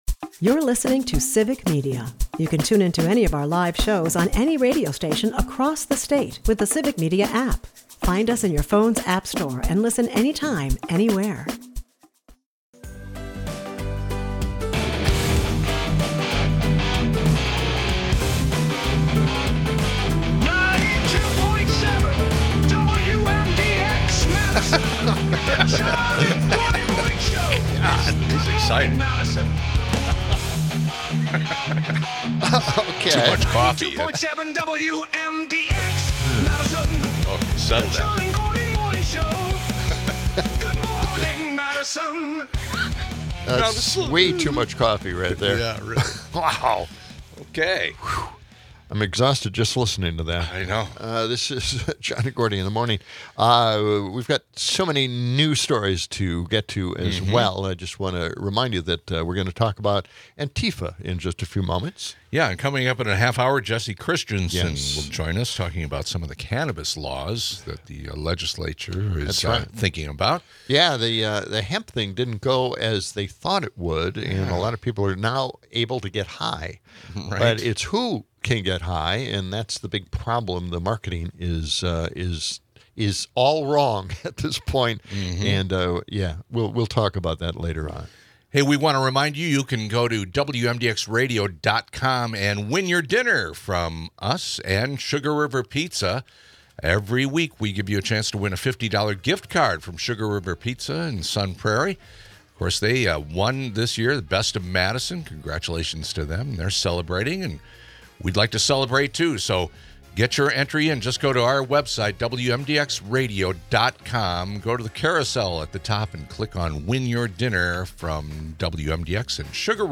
Amidst the madness, a MAGA-clad protester makes a bizarre cameo, reminding us of the ever-persistent political theatrics.